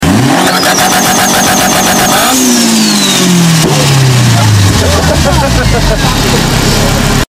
500hp Boosted Honda Civic Crazy sound effects free download
500hp Boosted Honda Civic Crazy Turbo Flutter Sound!!